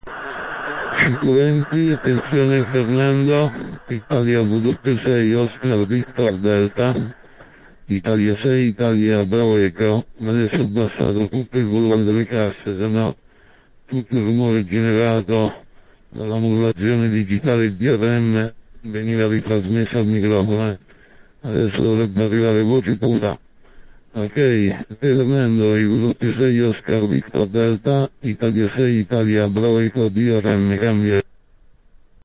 Registrazioni AUDIO formato MP3 tx voice FDMDV